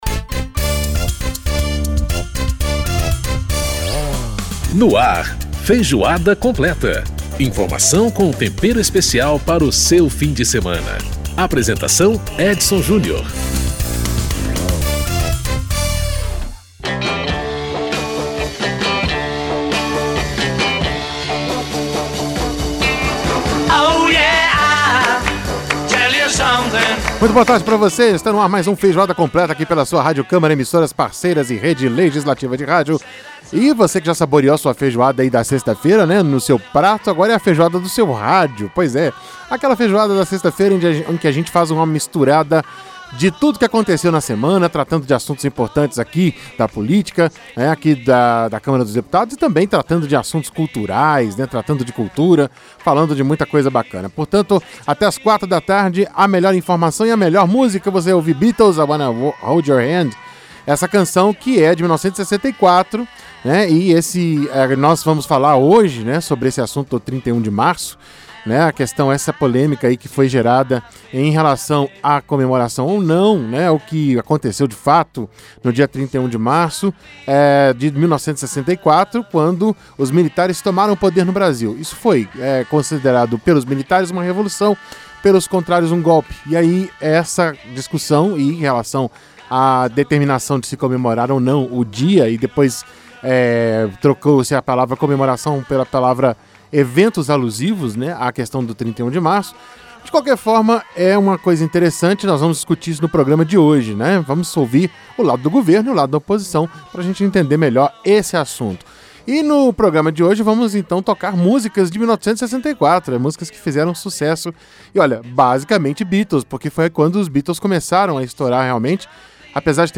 Debate com o deputado na Rádio Câmara.